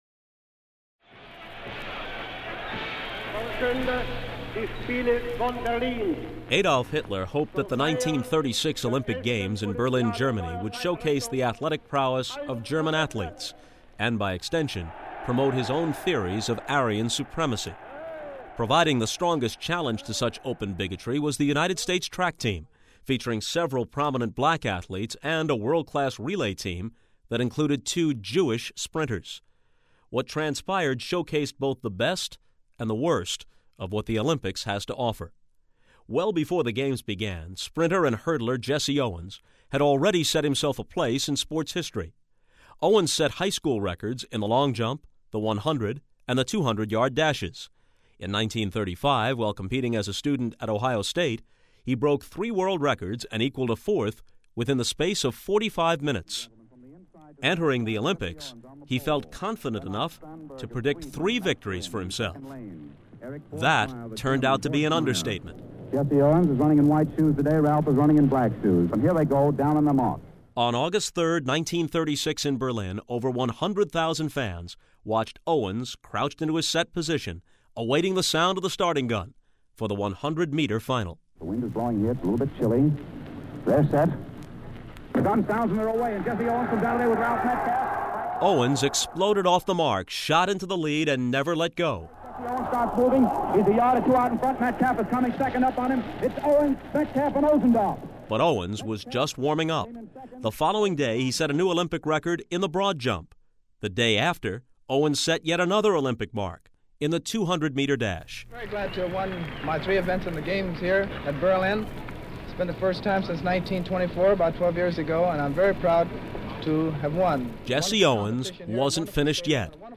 A 2 minute, 50 second look back at Jesse Owens' win in the '36 Olympics narrated by Bob Costas.
Jesse_Owens_Wins_Four_Gold_Medals-Bob_Costas.mp3